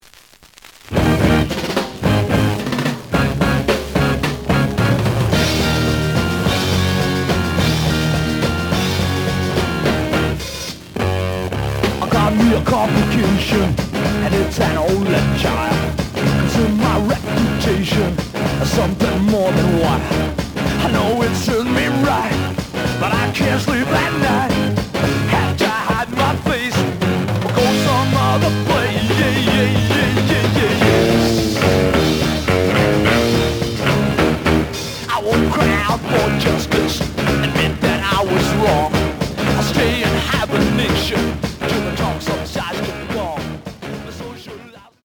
The audio sample is recorded from the actual item.
●Genre: Rock / Pop
Some noise on A side.